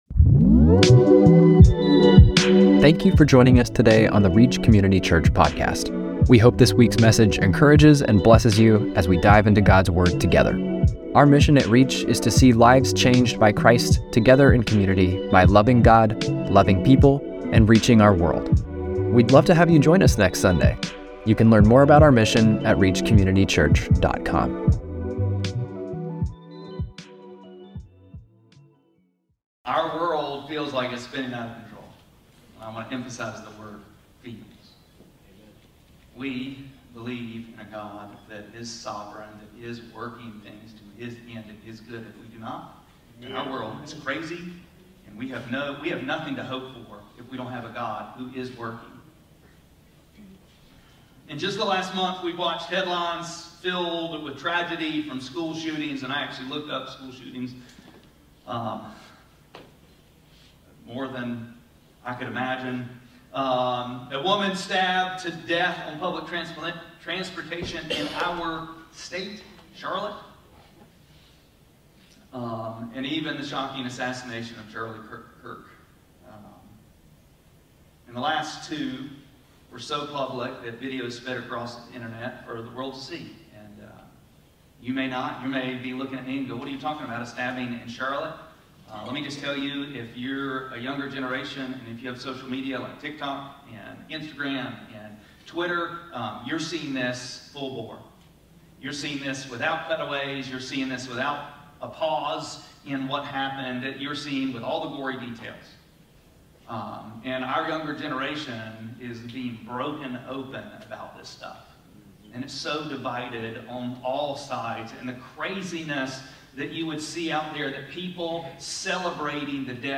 9-14-25-Sermon-.mp3